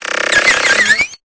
Cri de Rapion dans Pokémon Épée et Bouclier.